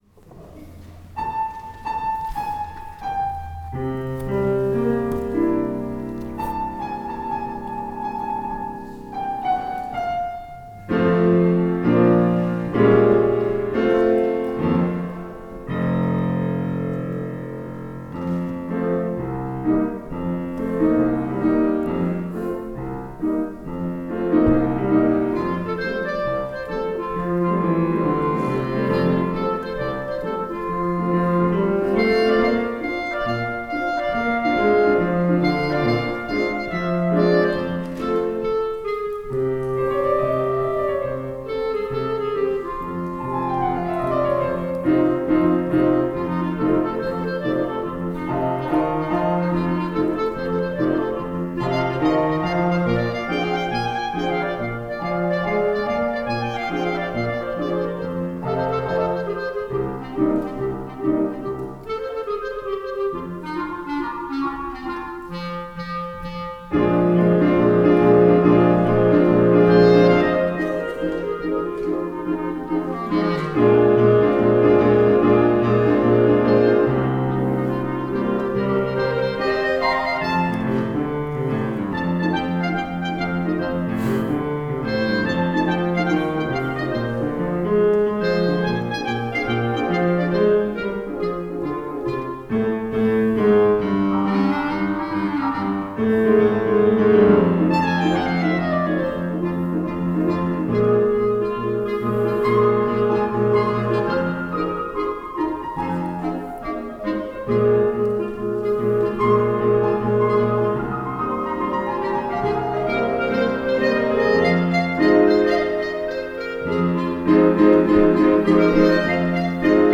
for Clarinet and Piano (2005)